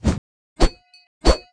attack_act_1.wav